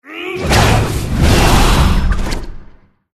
GameMpassetsMinigamesCjsnowEn_USDeploySoundGameplaySfx_mg_2013_cjsnow_attacksenseifire.mp3